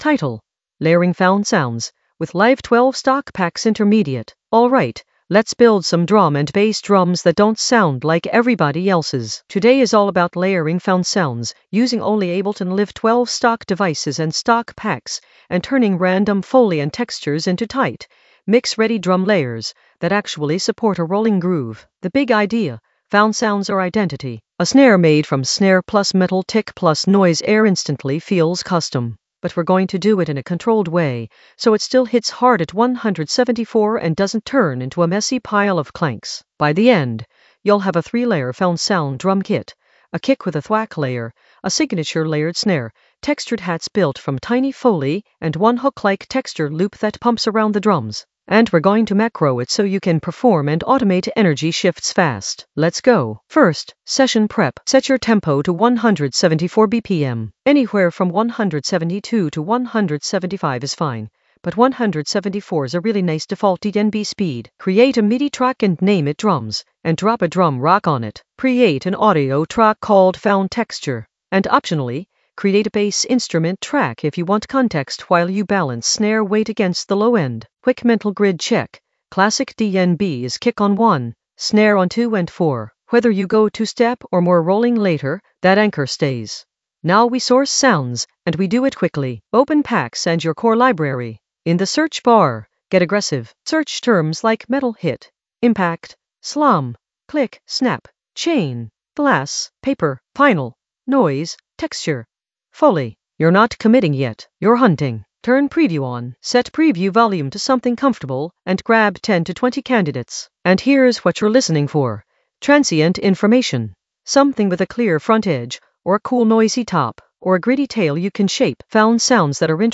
Narrated lesson audio
The voice track includes the tutorial plus extra teacher commentary.
layering-found-sounds-with-live-12-stock-packs-intermediate-sampling.mp3